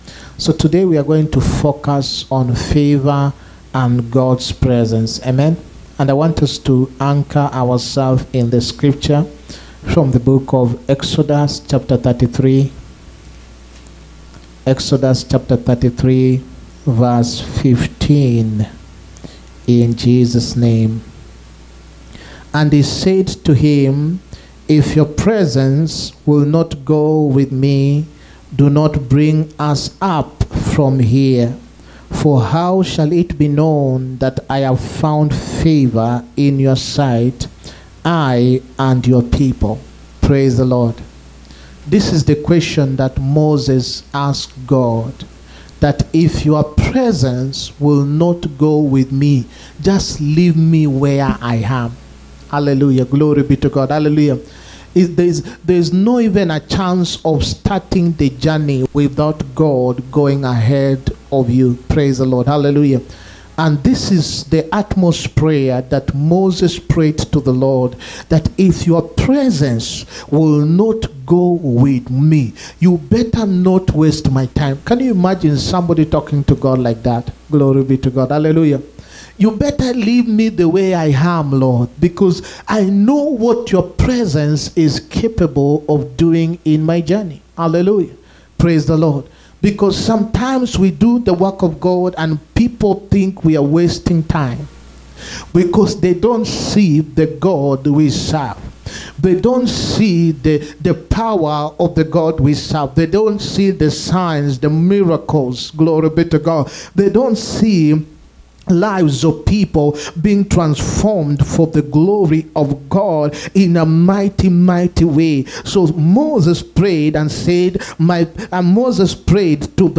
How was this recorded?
HEALING-PROPHETIC-AND-DELIVERANCE-SERVICE